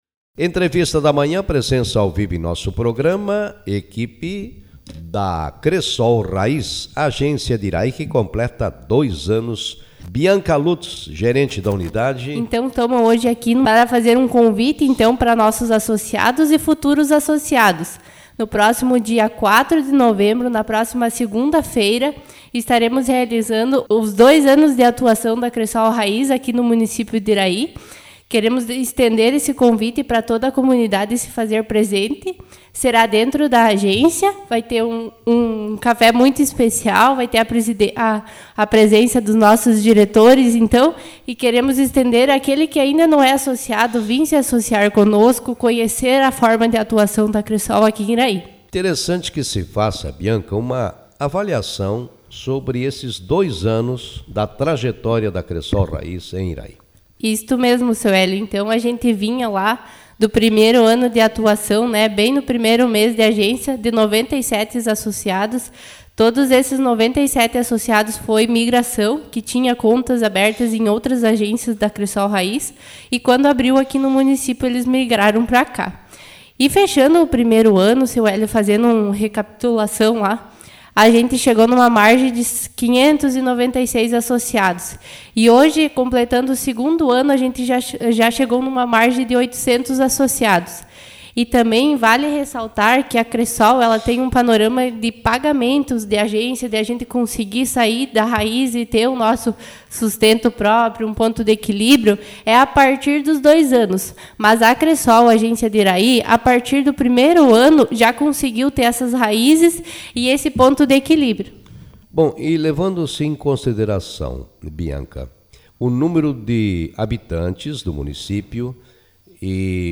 Agência da Cresol Raiz completa dois anos de atividade em Iraí Autor: Rádio Marabá 30/10/2024 Manchete Na segunda-feira, 04 de novembro, a agência da Cresol Raiz completa dois anos de atividade no município de Iraí.